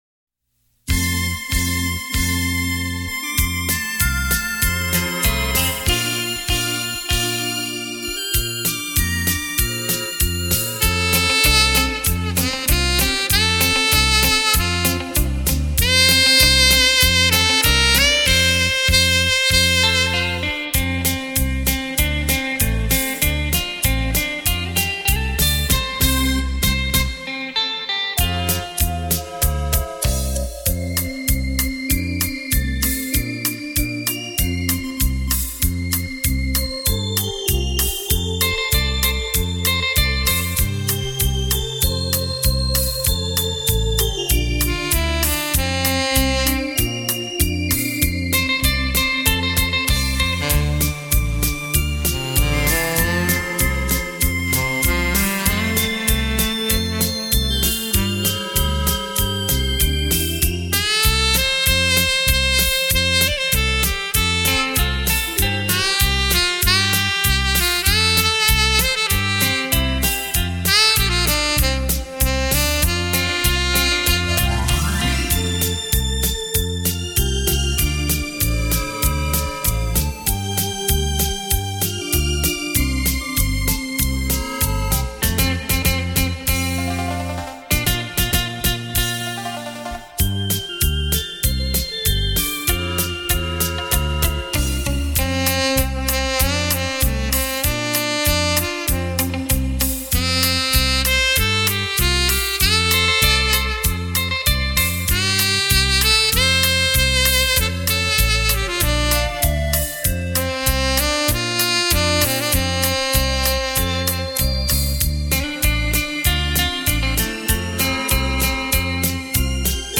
雷射版[音响测试带] 现场演奏